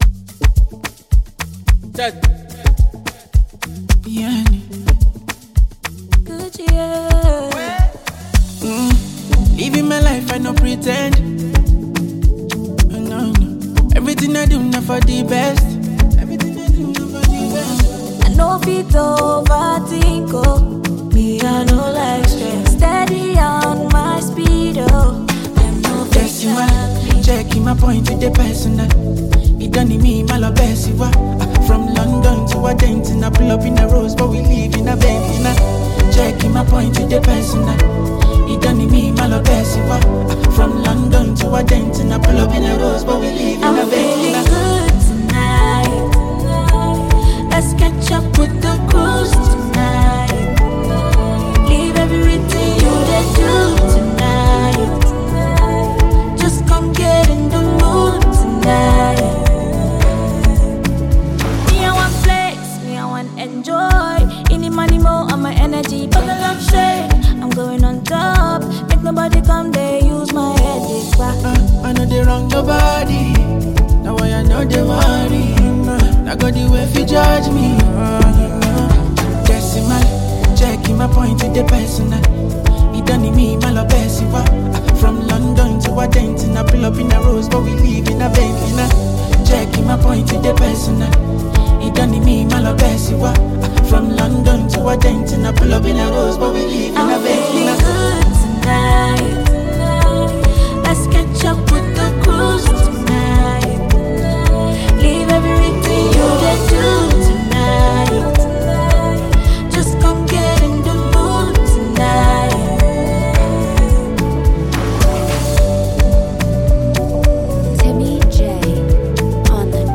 thrilling new gbedu song